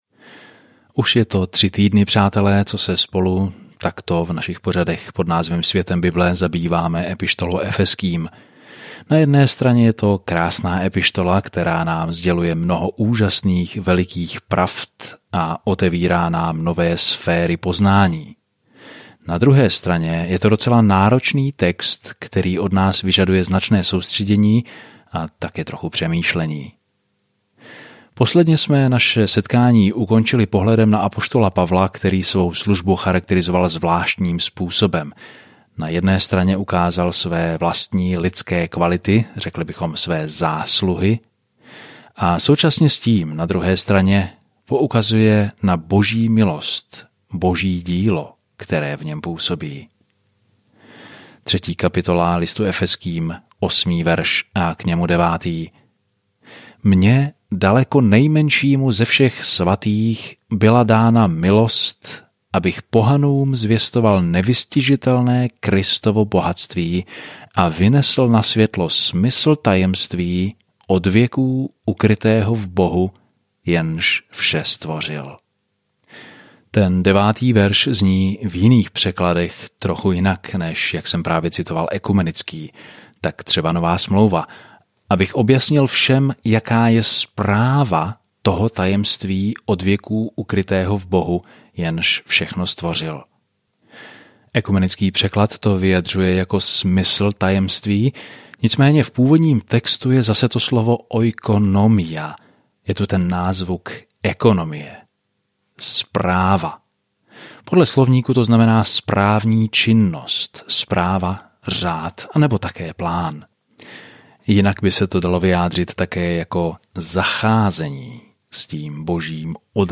Písmo Efeským 3:10-14 Den 14 Začít tento plán Den 16 O tomto plánu Z krásných výšin toho, co Bůh chce pro své děti, list Efezským vysvětluje, jak chodit v Boží milosti, pokoji a lásce. Denně procházejte Efezským, zatímco budete poslouchat audiostudii a číst vybrané verše z Božího slova.